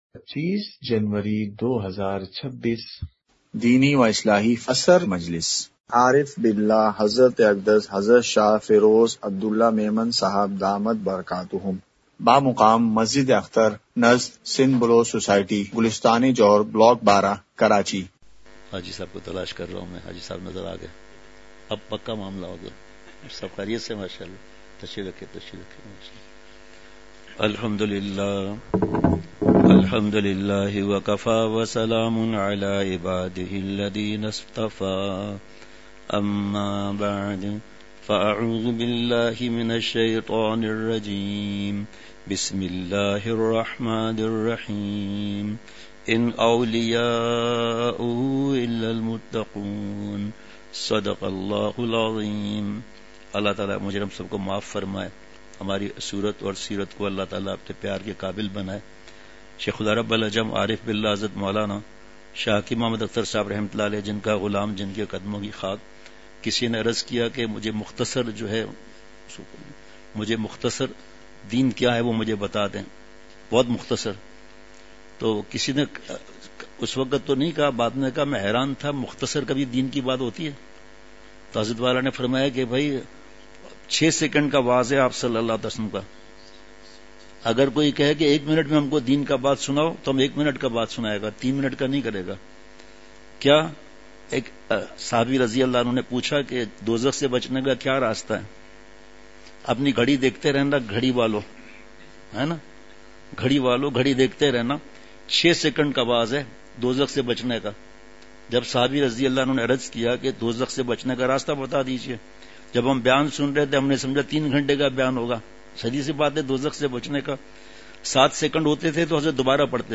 عصر مجلس ۲۵ جنوری ۲۶ء:سادگی والا نکاح برکت والا ہوتا ہے!
*مقام:مسجد اختر نزد سندھ بلوچ سوسائٹی گلستانِ جوہر کراچی*